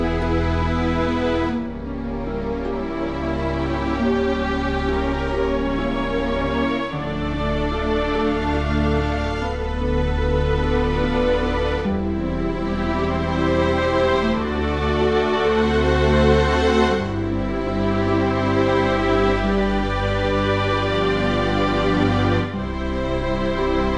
no Backing Vocals Crooners 4:45 Buy £1.50